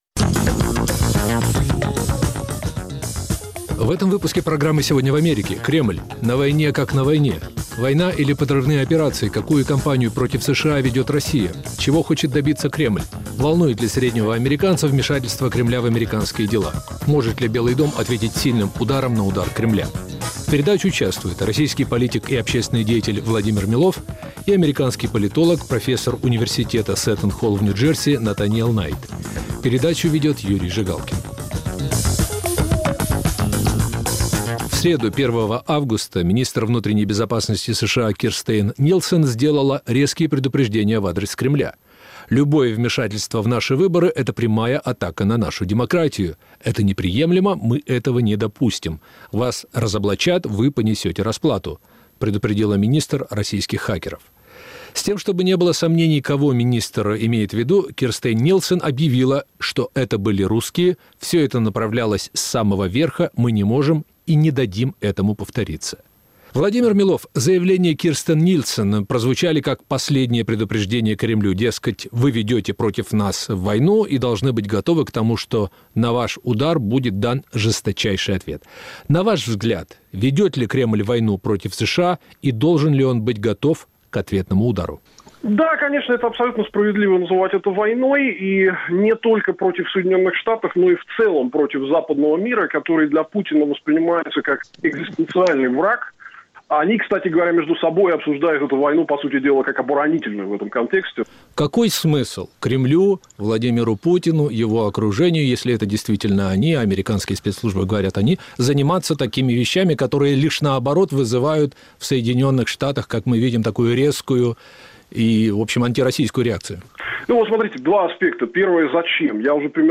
В передаче участвуют: российский политик и общественный деятель Владимир Милов и американский политолог